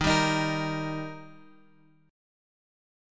D# chord {x 6 5 x 4 6} chord
Dsharp-Major-Dsharp-x,6,5,x,4,6.m4a